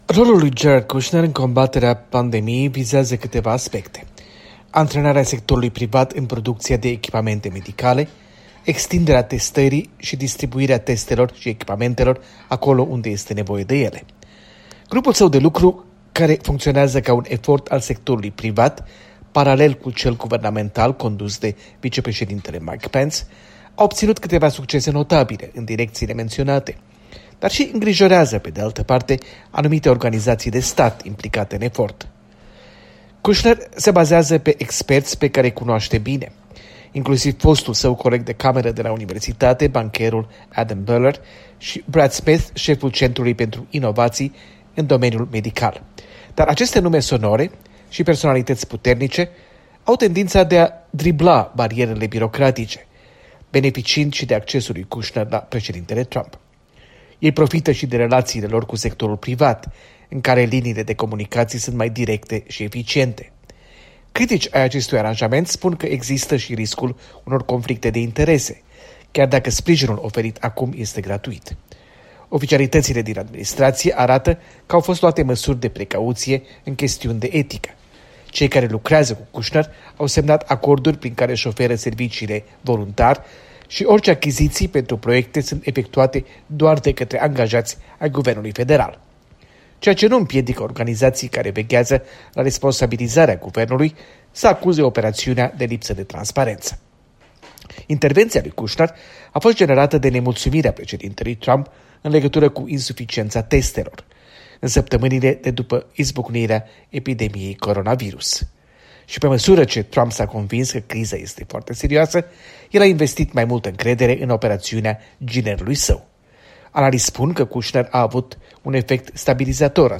Corespondență de la Washington - Jared Kushner